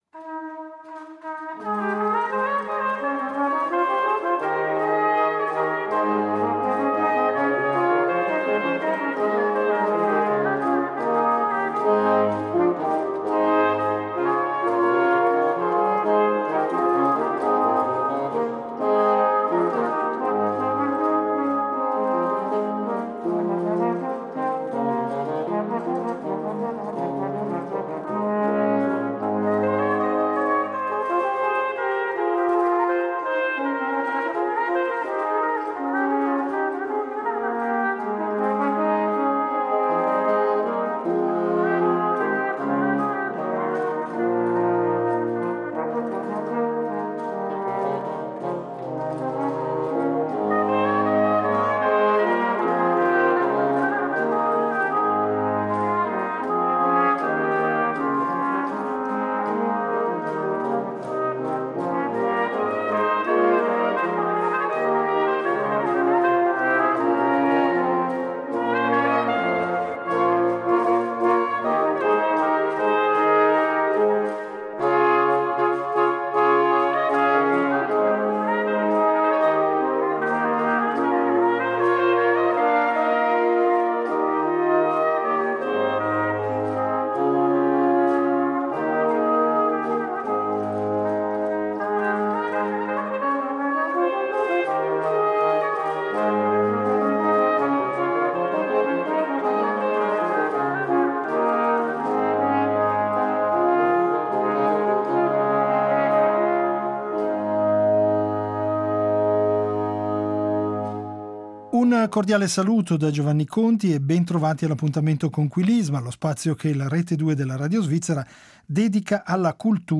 L’organico di tali composizioni era alla base pensato per cantanti accompagnati dall’organo, ma era prassi comune sostenere e arricchire il contrappunto vocale con strumenti a fiato o ad arco, alle volte addirittura sostituendo le voci con gli strumenti.
Protagonista della puntata l’ensemble svizzero Concerto Scirocco.